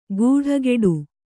♪ guḍhageḍu